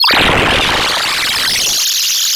Track 05 - Synth Blurble OS 01.wav